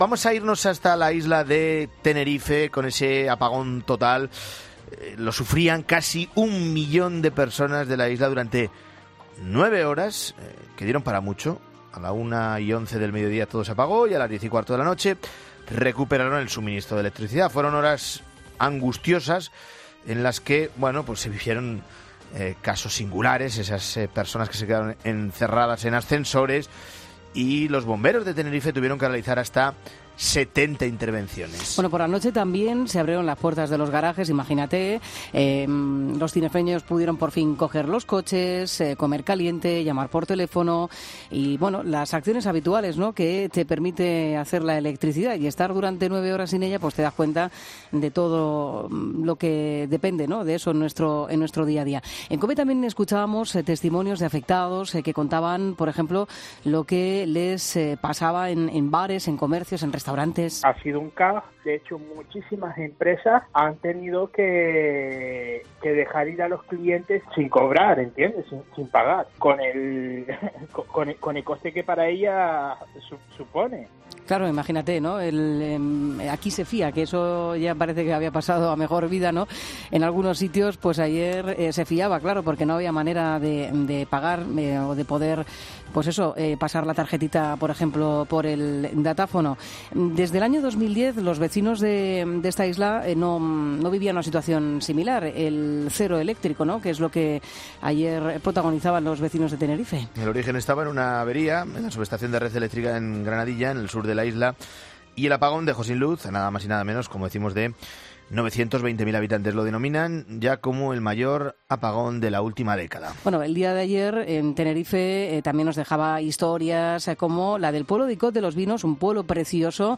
El alcalde, Francis González, ha explicado en Mediodía COPE que lo lograron gracias a los grupos electrógenos.